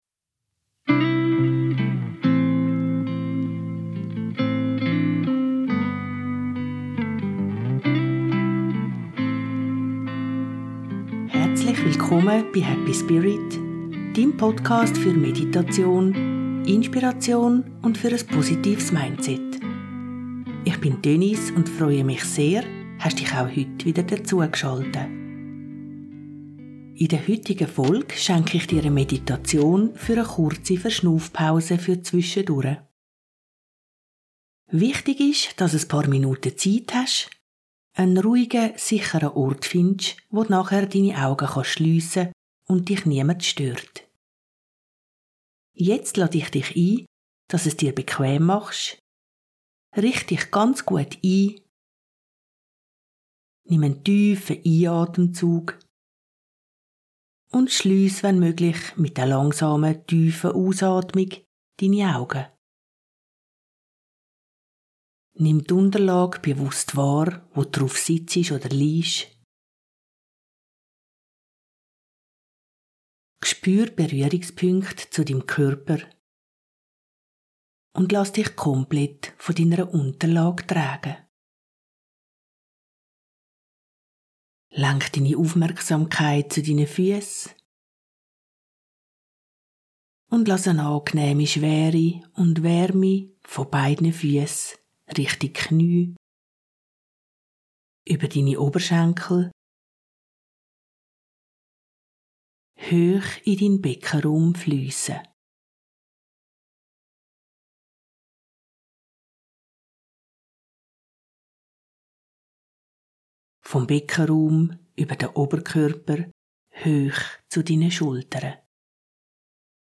Deine kurze Verschnaufpausen-Meditation . . .